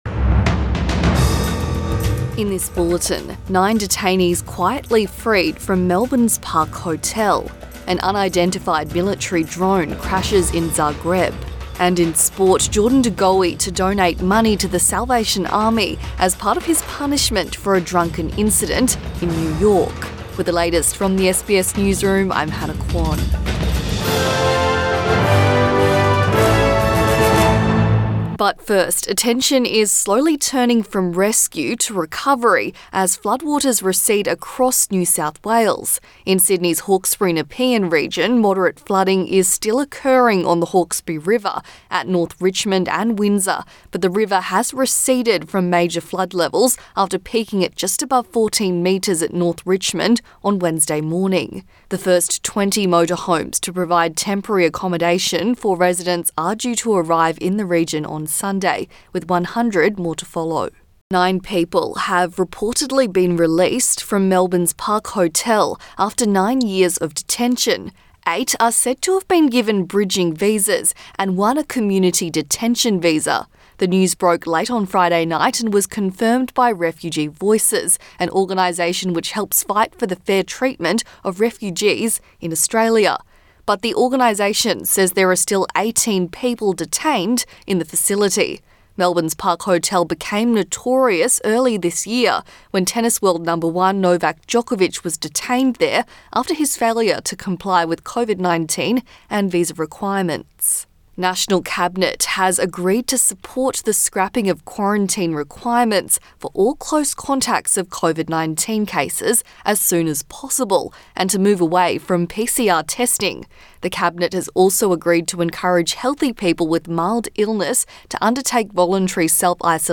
AM bulletin 12 March 2022